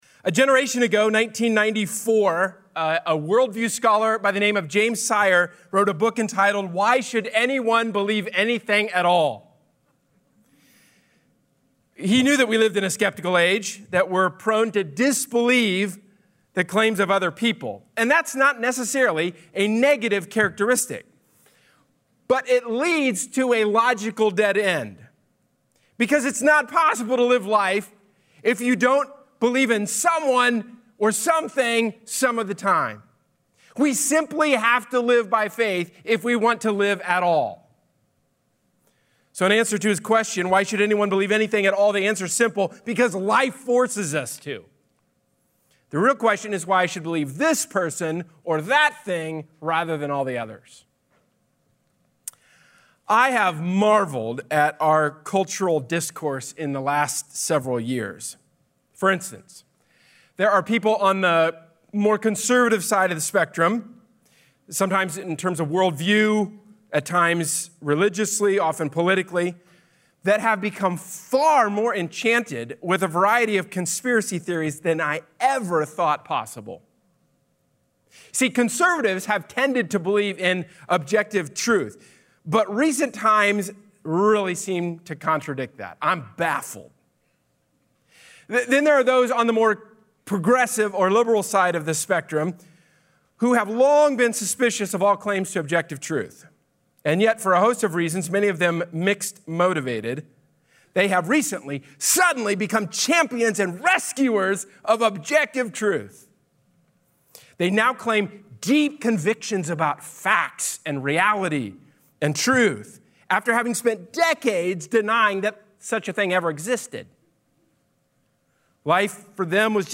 A sermon from the series "Something to Believe In."